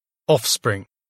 offspring [ɔfsprɪŋ]